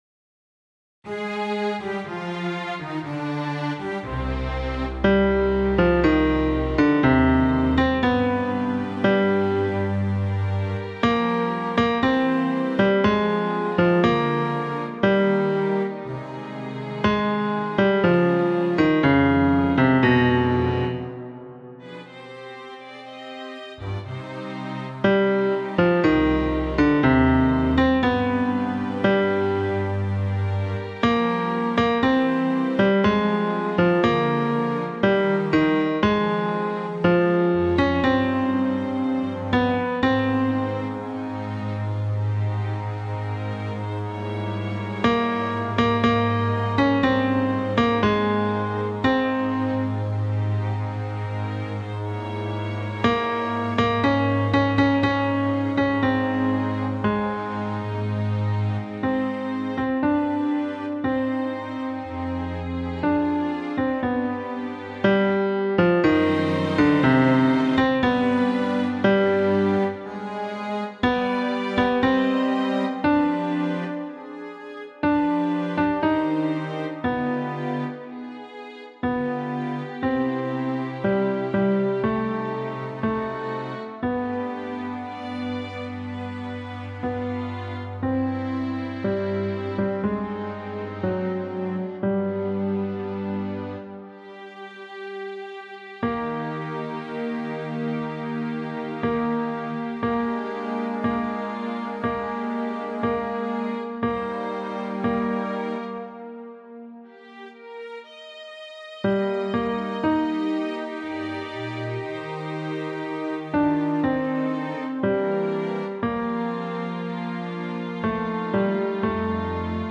Tenor
Mp3 Música
4.-Virgo-Virginum-TENOR-MUSICA.mp3